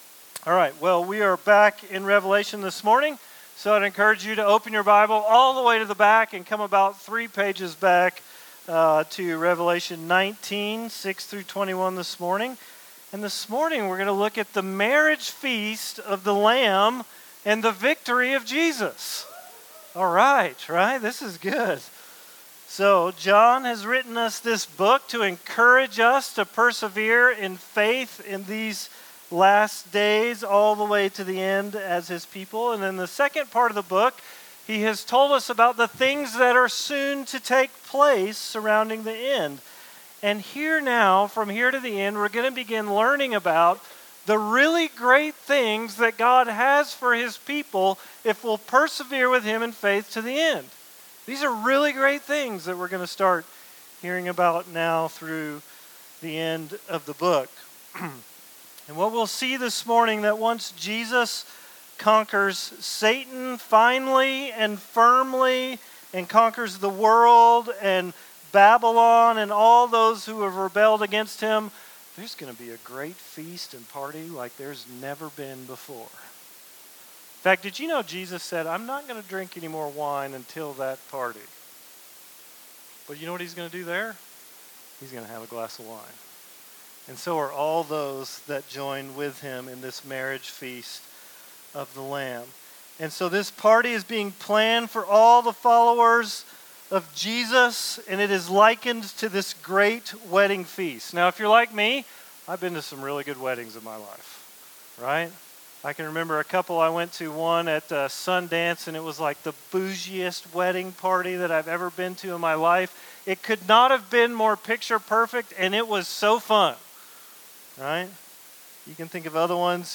Today’s sermon is on Revelation 19:6-21. In these verses the multitude of the redeemed saints are celebrating God’s reign over all things and that the marriage feast of the Lamb that has finally come. We will look at the theme of marriage in the Bible and what it teaches us about the Gospel.